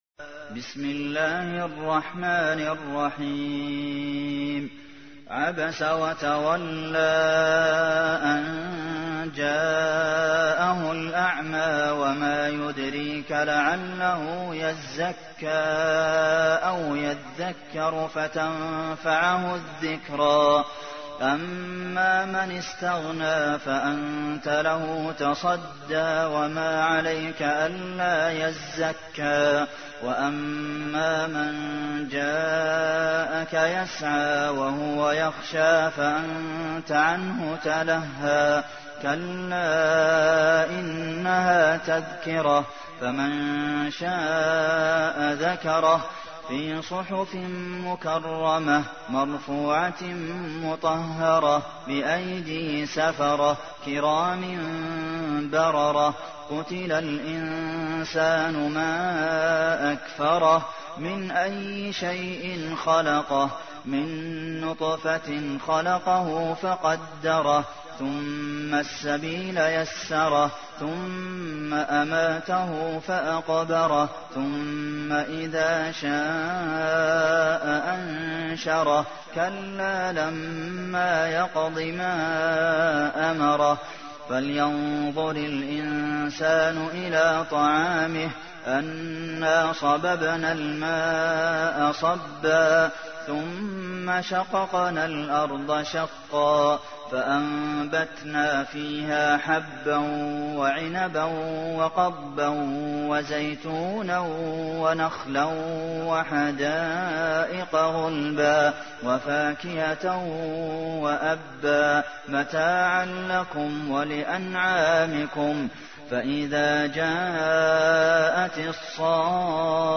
تحميل : 80. سورة عبس / القارئ عبد المحسن قاسم / القرآن الكريم / موقع يا حسين